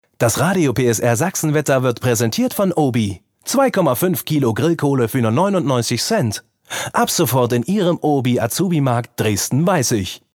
ein Junger dynamischer und frischer Sprecher - gesanglich und spielerisch ausgebildet.
Kein Dialekt
Sprechprobe: Industrie (Muttersprache):